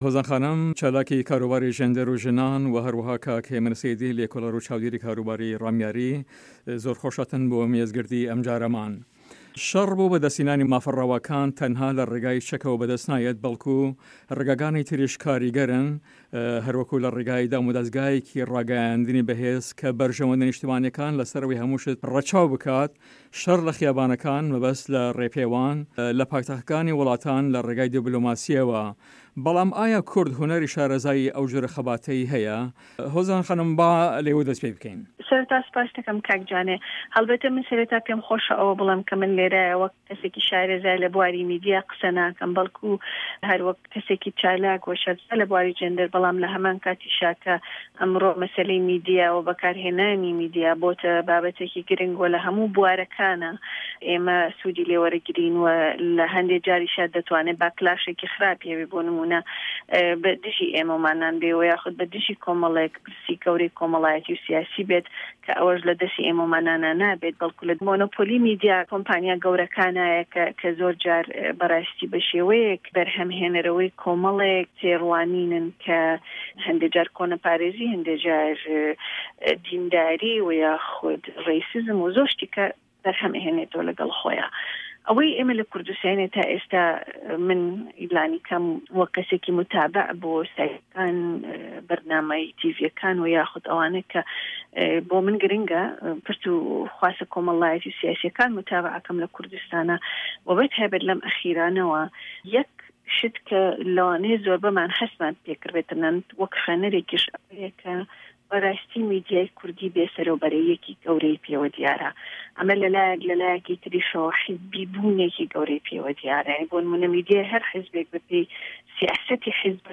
مێزگرد: کورد، سه‌رمایه‌گوزاری کورد به‌ گیان به‌ختوه‌کانیدا له‌ شه‌ری دژ به‌ داعش